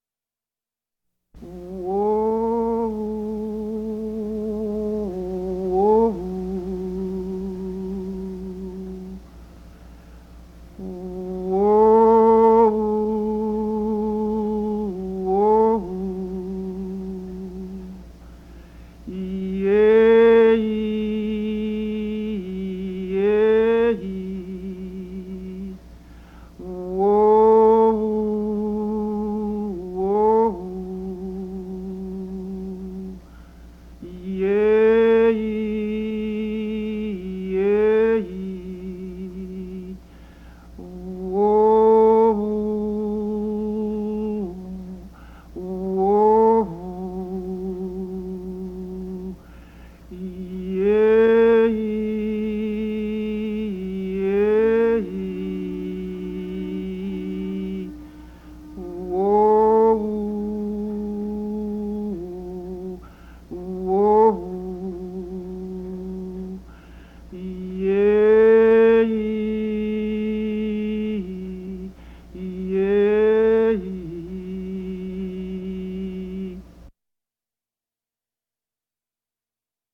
Field Calls
Father's Call
fieldcalls.mp3